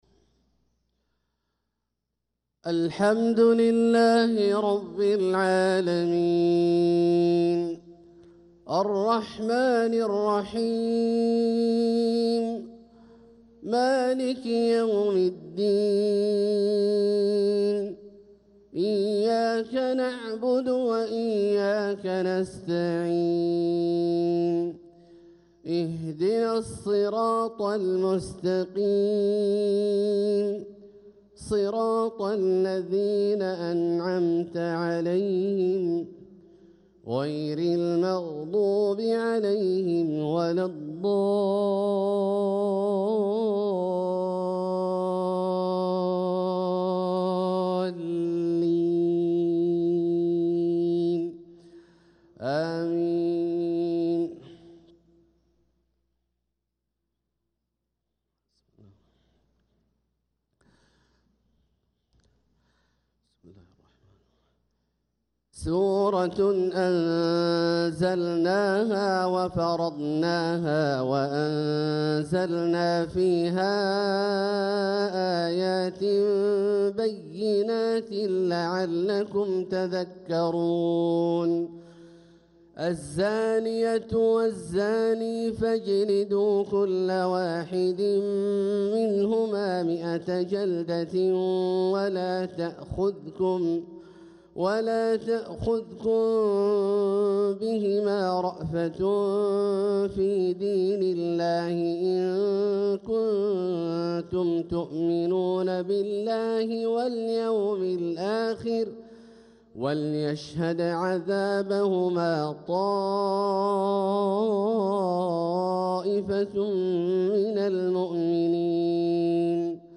صلاة الفجر للقارئ عبدالله الجهني 24 جمادي الأول 1446 هـ
تِلَاوَات الْحَرَمَيْن .